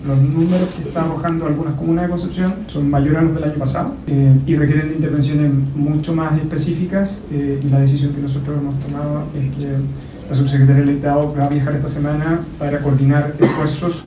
Ante estos recientes hechos, el Ministro de Seguridad Pública, Luis Cordero, señaló que el Gran Concepción requiere una intervención más específica.